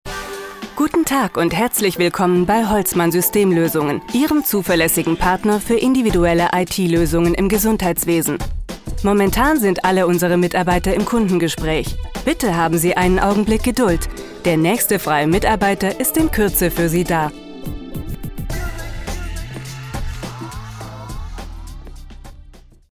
Sprecherin aus Berlin mit vielseitig einsetzbarer Stimme - frisch/jugendlich - sachlich/seriös - sanft & warm.
Sprechprobe: Sonstiges (Muttersprache):